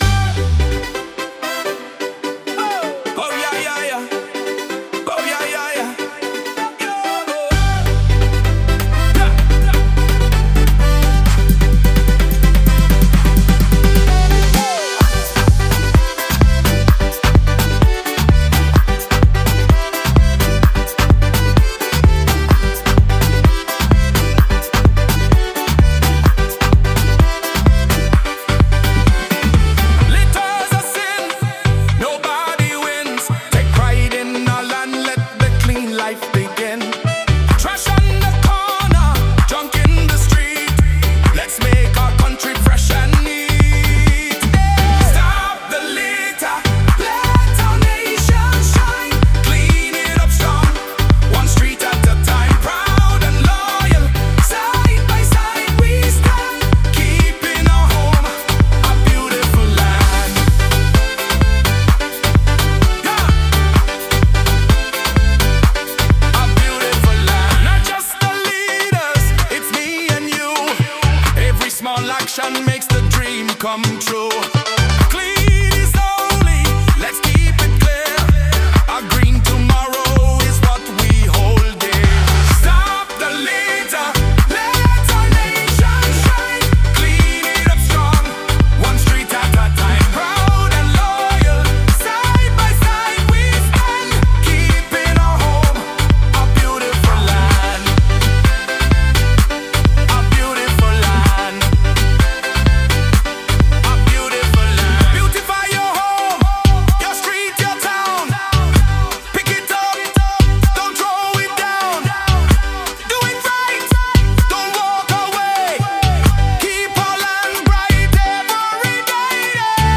A modern environmental anthem.